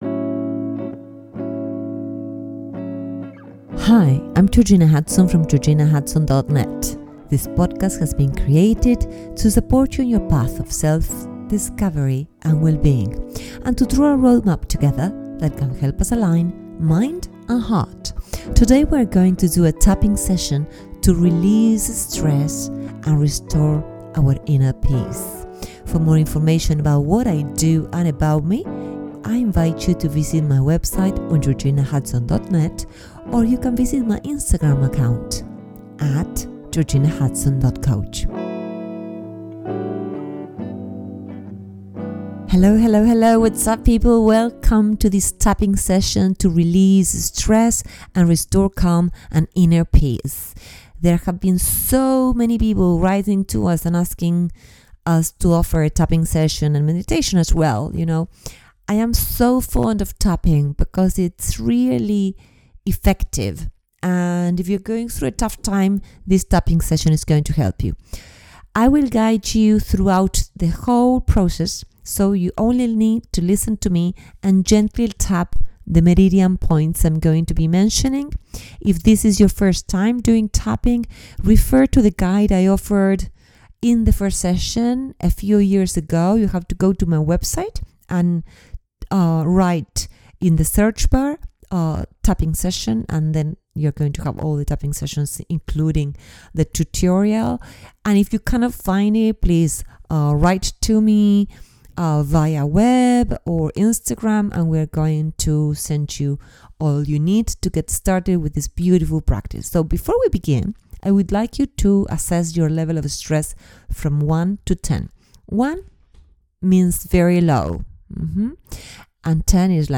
What can you expect from this session? 1. Releasing stress: We will identify and release the built-up tension in your body and mind. 2. Restoring calm: As we progress, you’ll learn how to create a peaceful space within. 3. Positive reinforcement: We’ll finish the session with powerful affirmations to help you maintain emotional balance. All you need to do is get comfortable, take a deep breath, and follow my words while we work through the Tapping points.